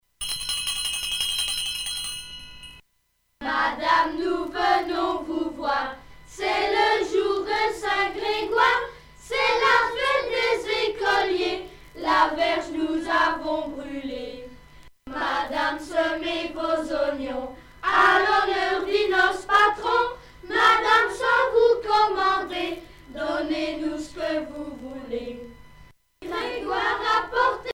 quête calendaire
Pièce musicale éditée